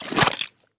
Звуки убийства, трупа
Звук вытаскивания глаз из человека